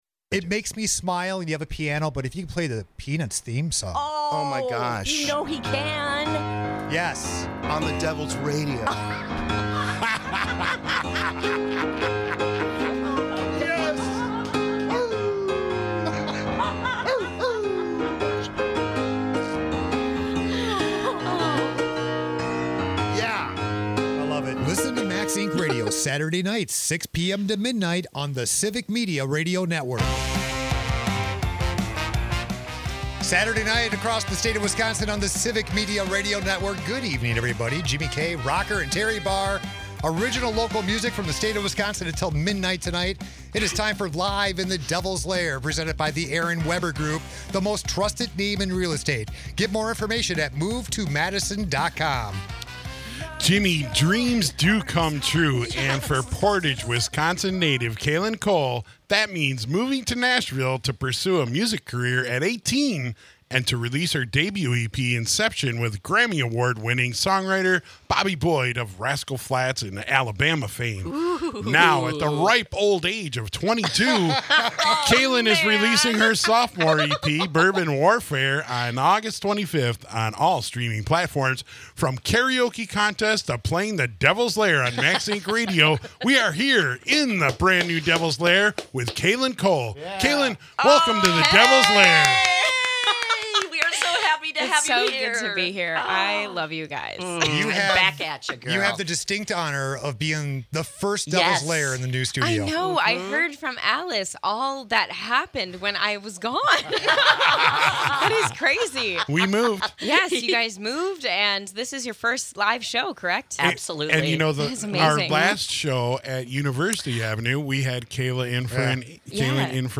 performance was the first in our new studio